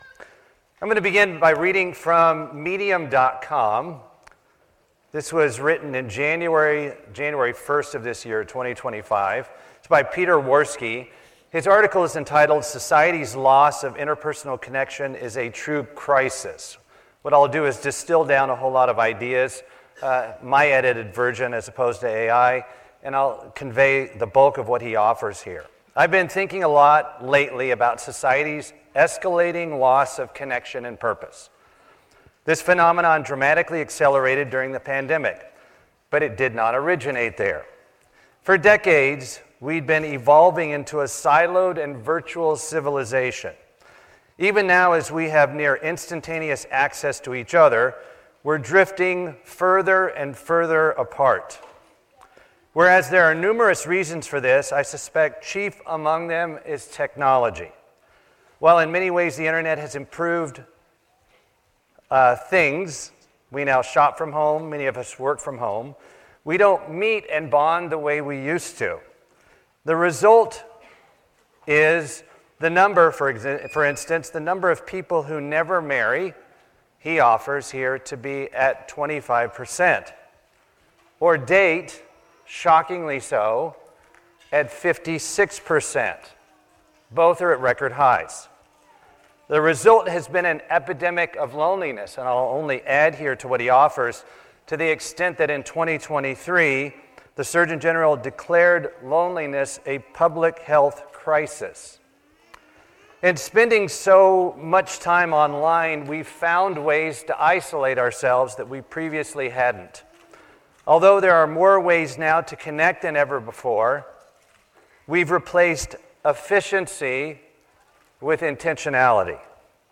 Sermons
Given in Lihue, Hawaii